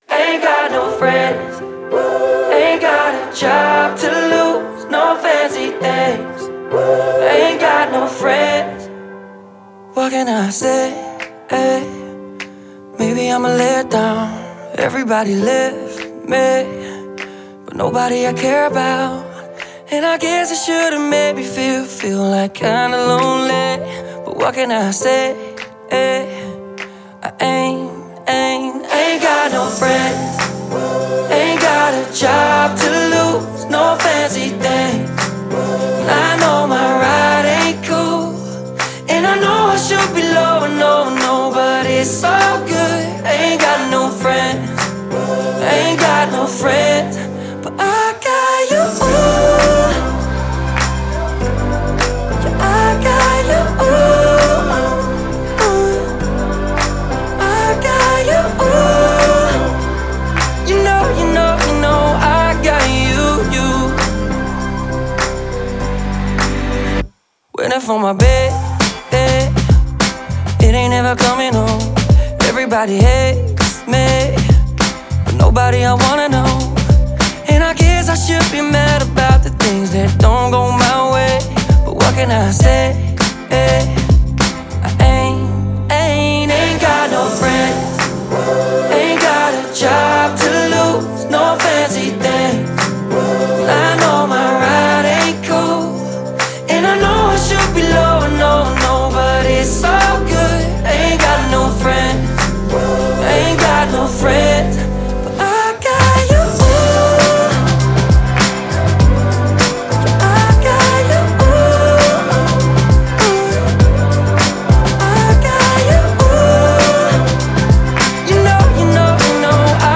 это яркая поп-песня